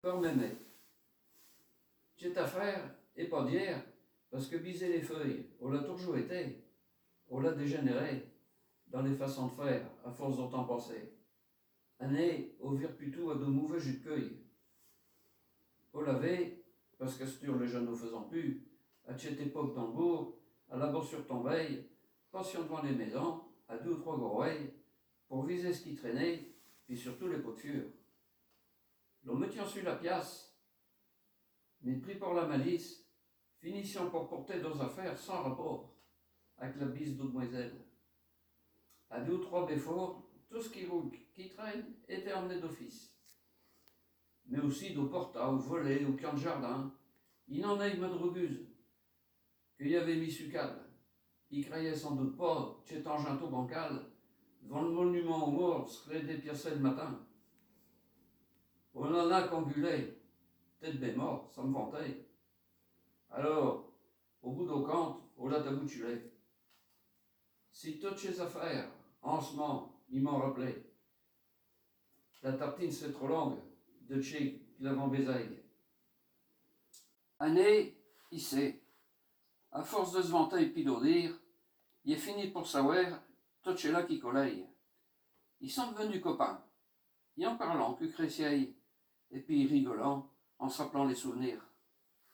Genre récit
Poésies en patois